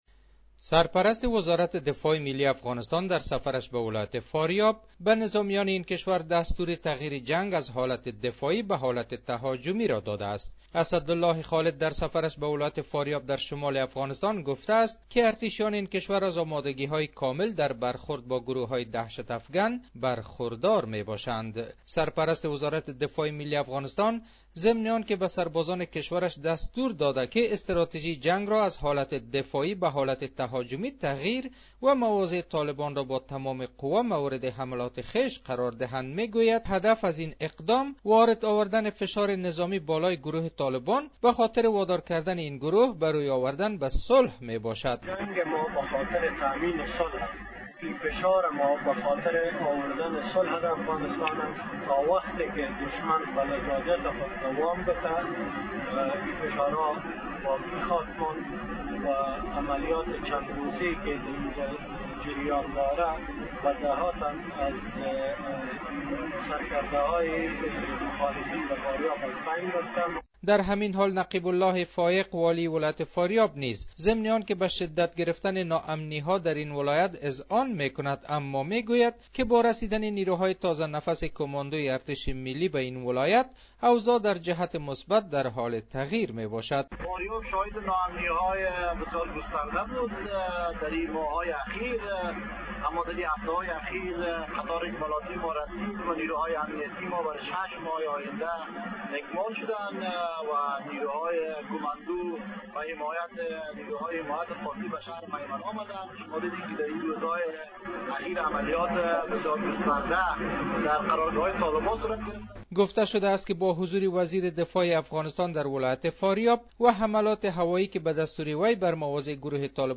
گزارش : تغییر مواضع ارتش ملی افغانستان در برابر طالبان